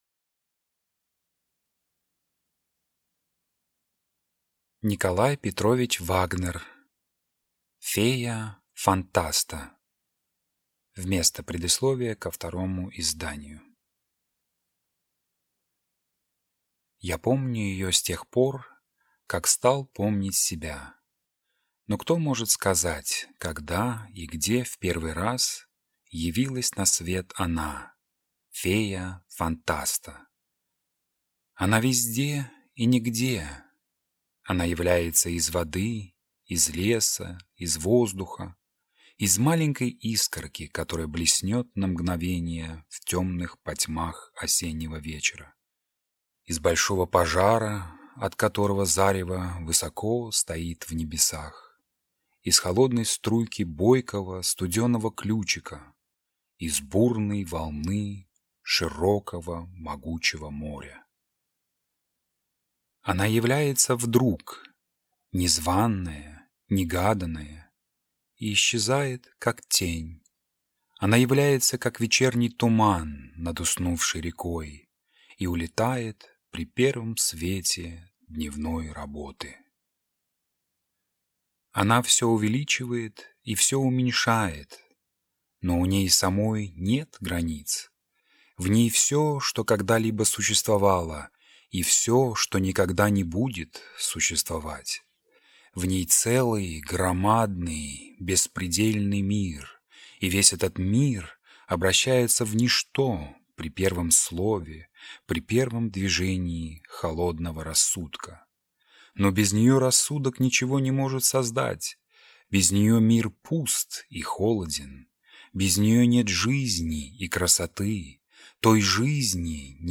Аудиокнига Фея Фантаста | Библиотека аудиокниг